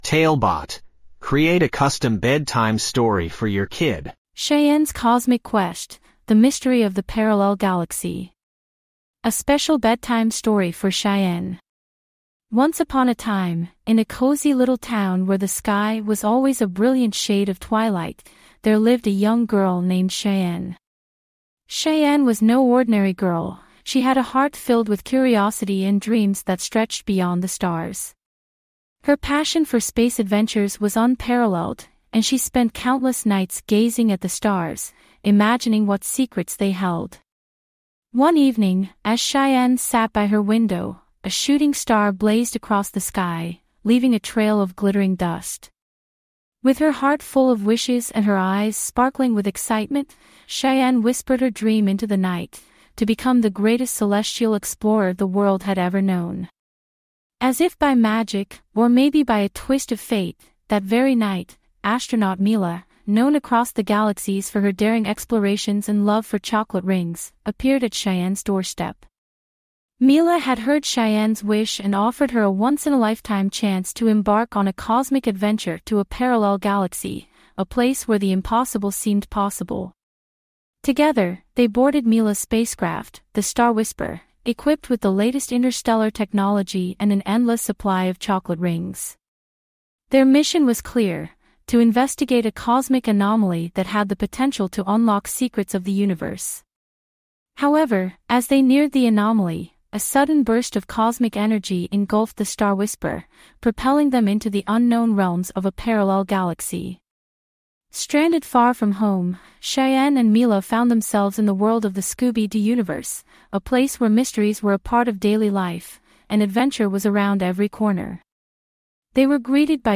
5 minute bedtime stories.
TaleBot AI Storyteller
Write some basic info about the story, and get it written and narrated in under 5 minutes!